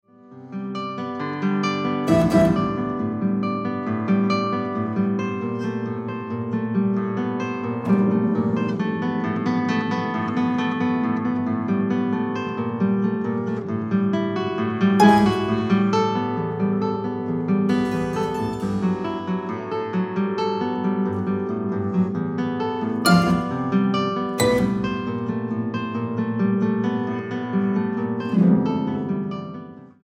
guitarra
clavecín
música original para guitarra y clavecín